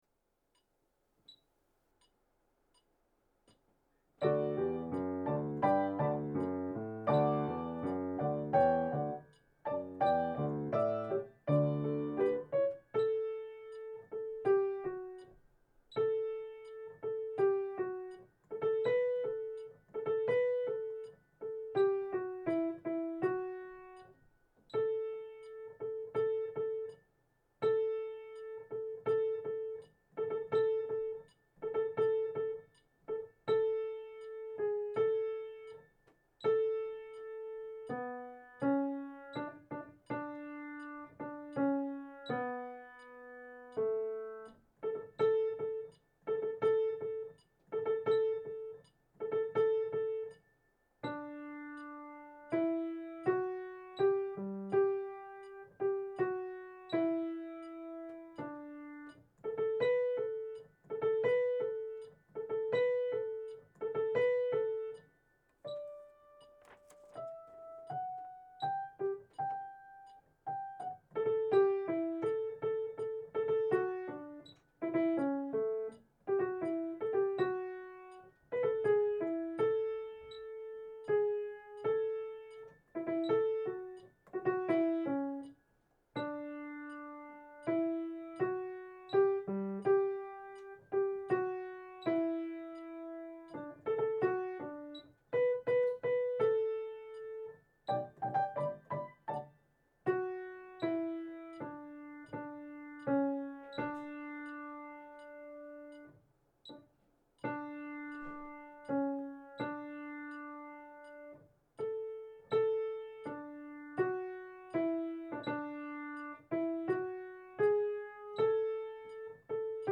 ハレルヤ_アルト.MP3